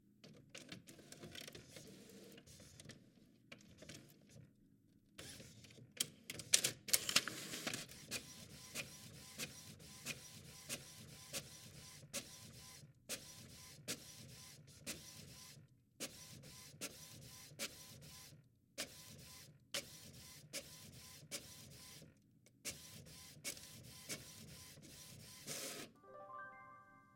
喷墨打印机
描述：在喷墨打印机上打印一张纸
Tag: 喷墨 打印机 印刷